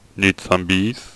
shaman_select1.wav